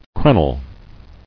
[cren·el]